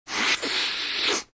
Звуки шмыганья носом